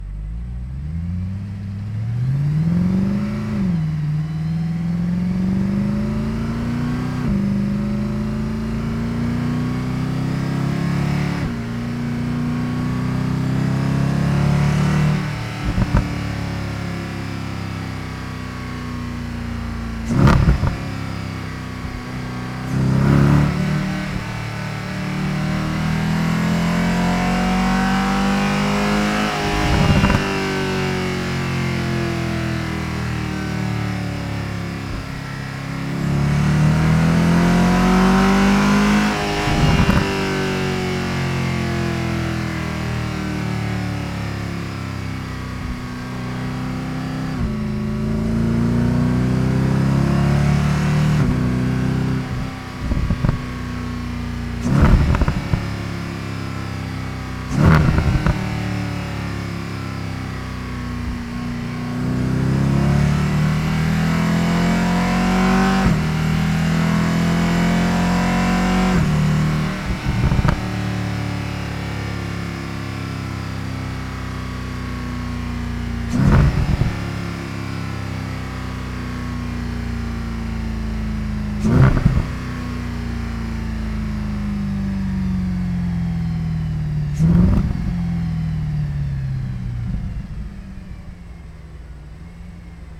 Auspuff Active Sound Einbausätze
Realistischer Klang: Erleben Sie echten Auspuffsound per Knopfdruck, ein- und ausschaltbar nach Belieben.